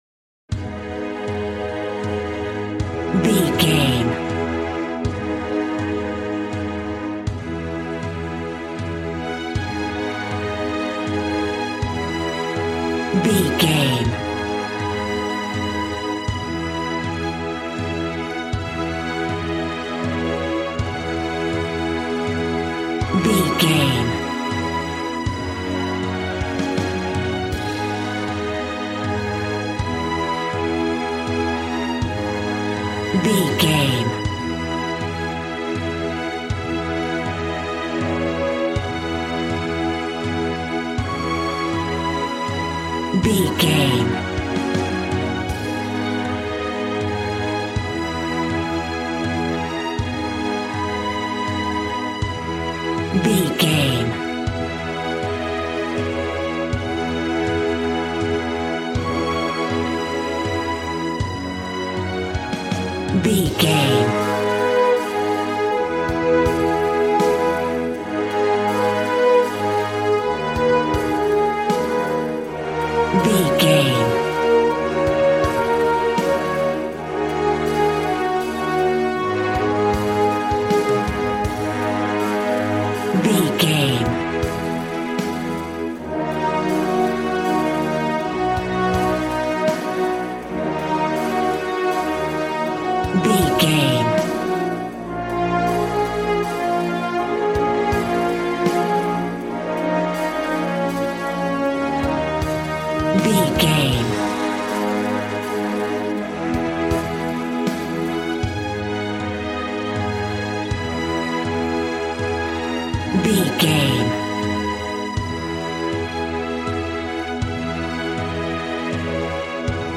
Aeolian/Minor
A♭
dramatic
strings
violin
brass